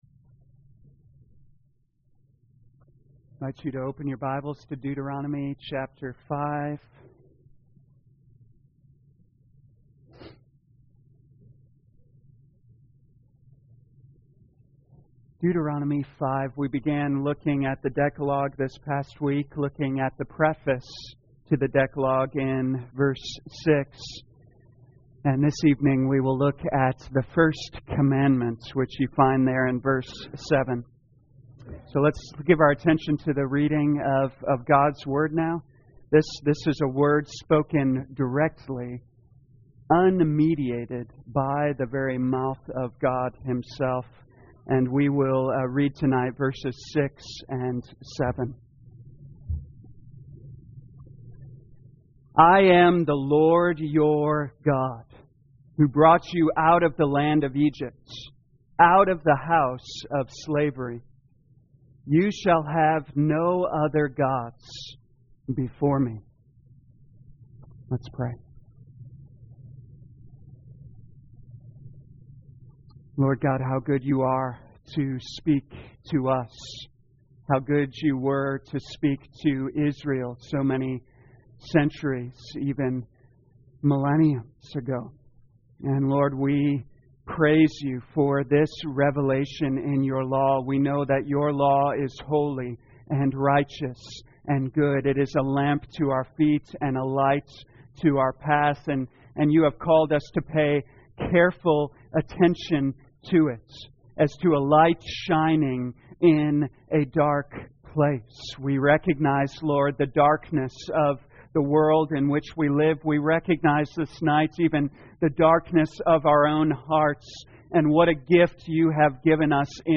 2021 Deuteronomy The Law Evening Service Download